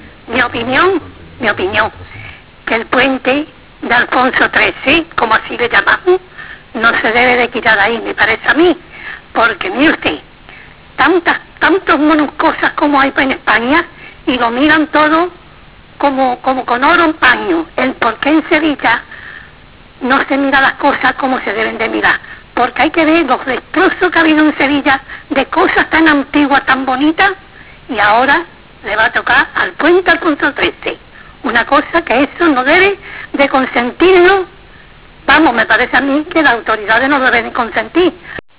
A finales de 1997 se organizó un debate televisivo en Onda Giralda Televisión, en el cual se mostraron las diferentes posturas existentes sobre el Puente de Alfonso XIII.